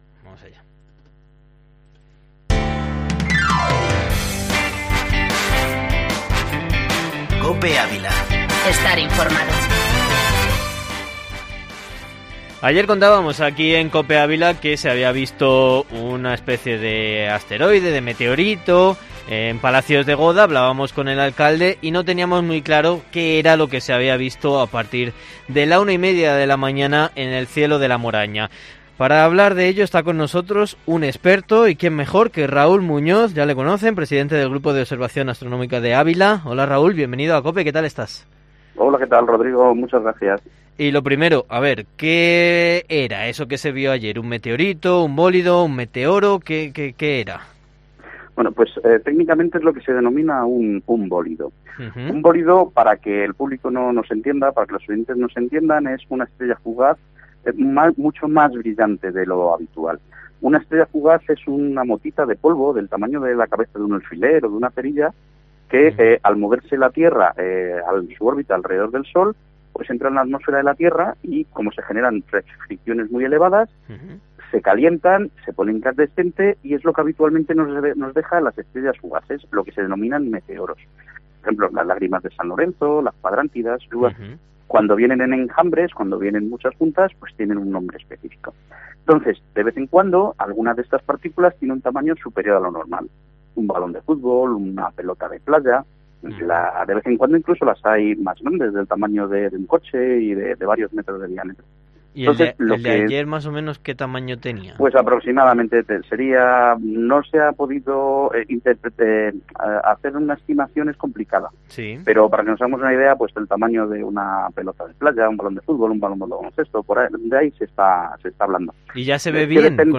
Herrera en COPE en Ávila Entrevista